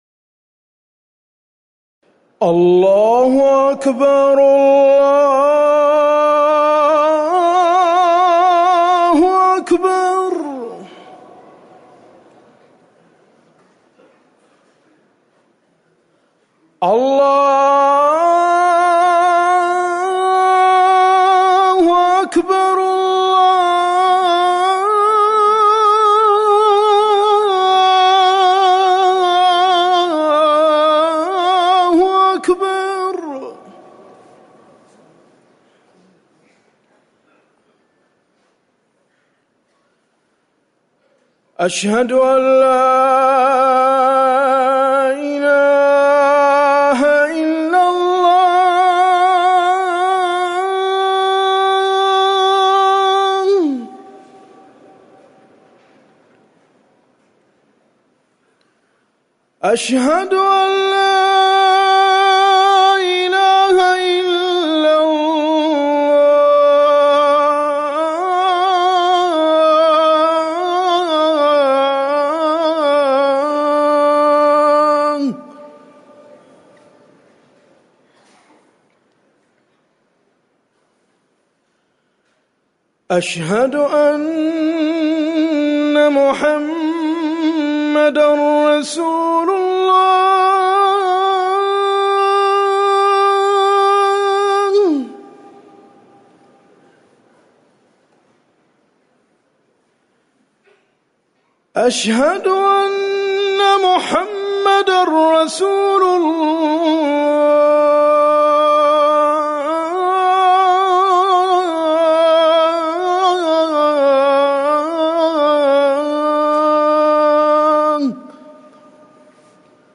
أذان العشاء
تاريخ النشر ٢١ صفر ١٤٤١ هـ المكان: المسجد النبوي الشيخ